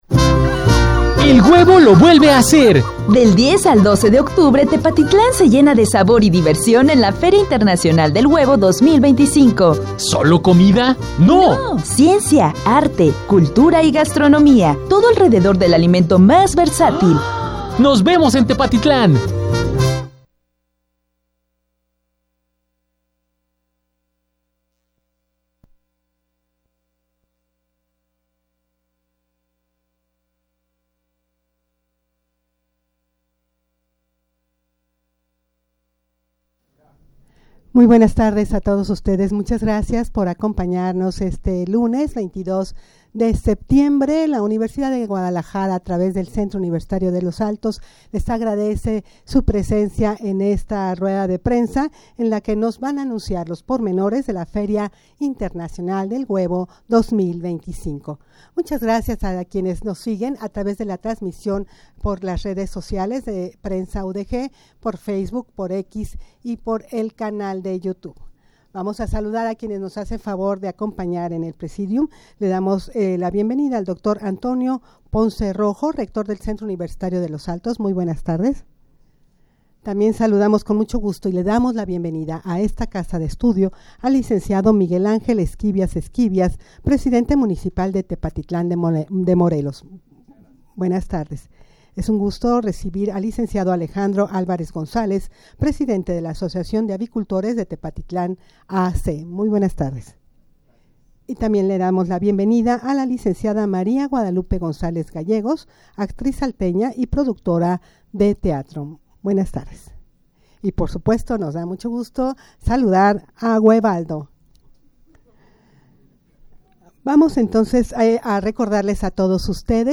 Audio de la Rueda de Prensa
rueda-de-prensa-para-anunciar-los-pormenores-de-la-feria-internacional-del-huevo-2025.mp3